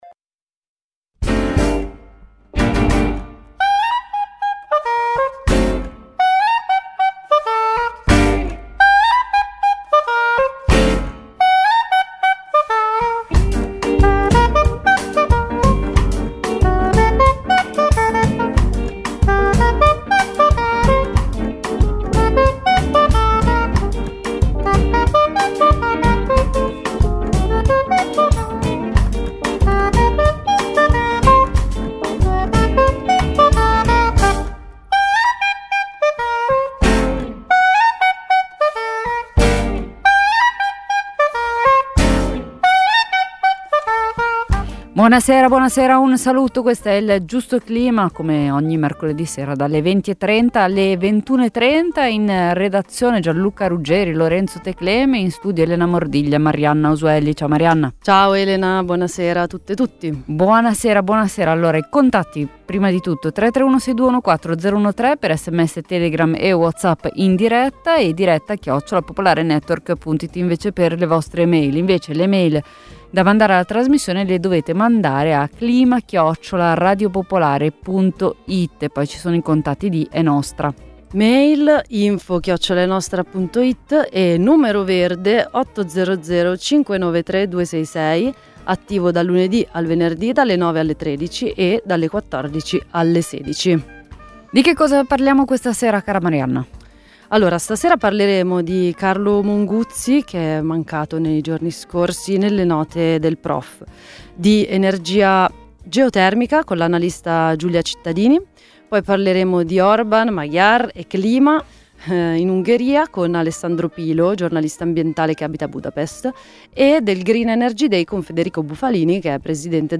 dalle 20.30 alle 21.30. In studio